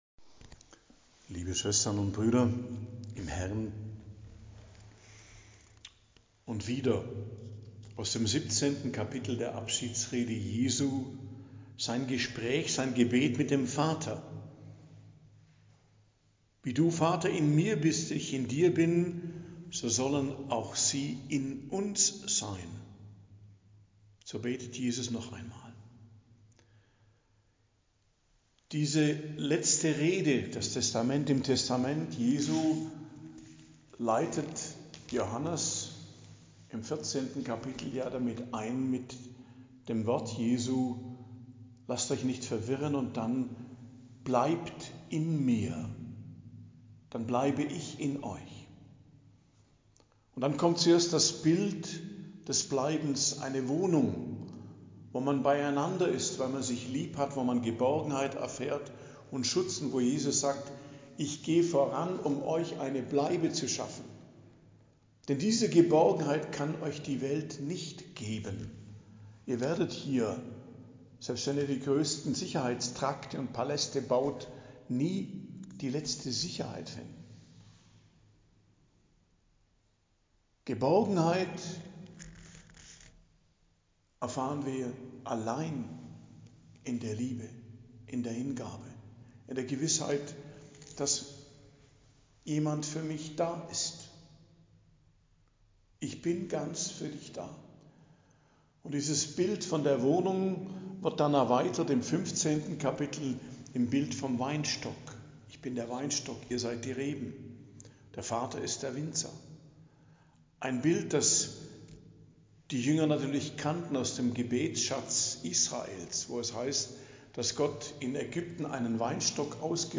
Predigt am Donnerstag der 7. Osterwoche, 5.06.2025